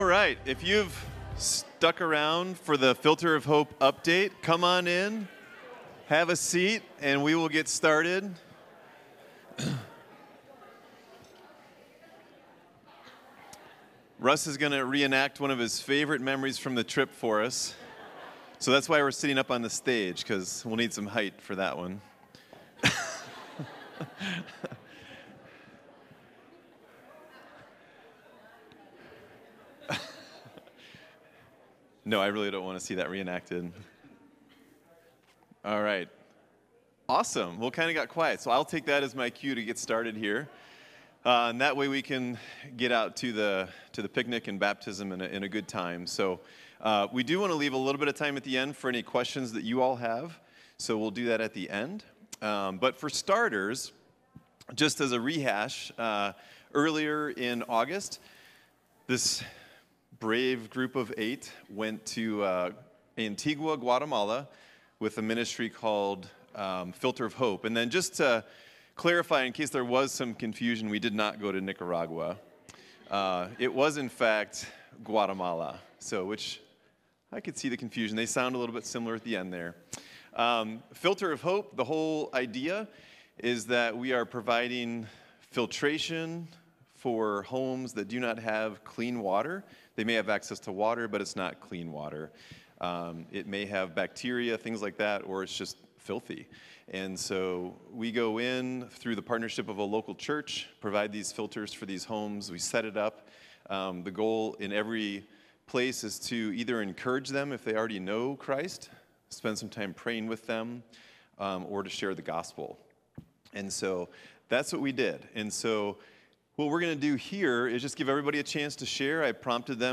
August 28, 2022 AUGUST 28, 2022 – Filter of Hope Recap – Mission Team Preacher: Mission Team Series: Missions Service Type: Sunday Morning Click the title above to see the video.